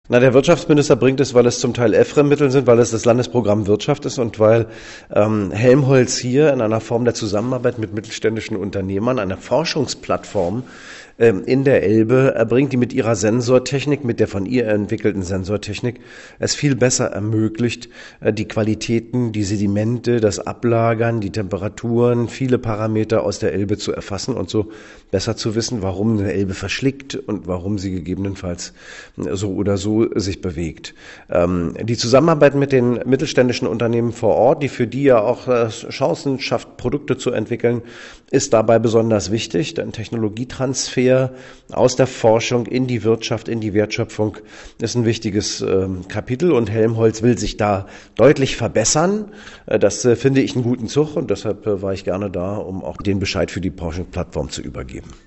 Weiter sagte Rohlfs (Audio starten – „im Browser anhören“)